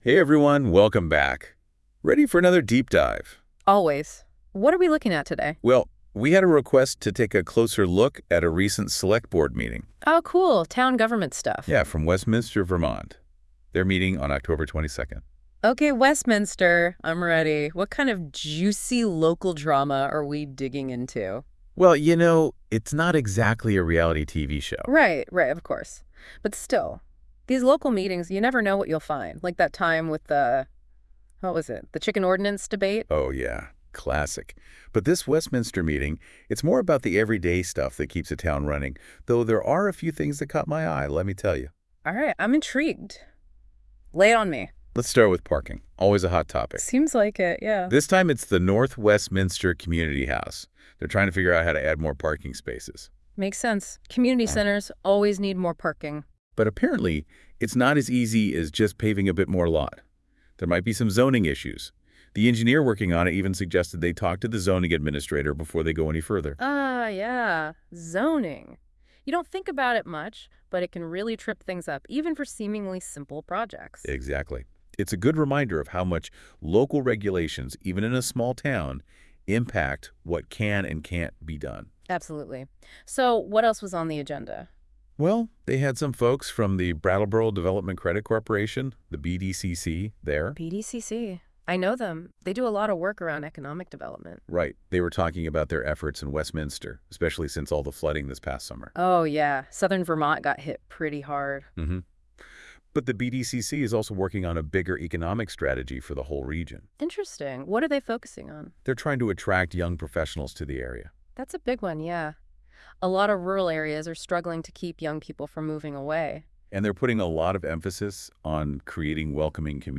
They’re back to discuss a variety of topics here in Westminster. This time, they are reviewing meeting minutes from the month of May.